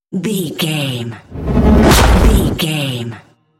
Whoosh to hit trailer
Sound Effects
Atonal
dark
futuristic
intense
tension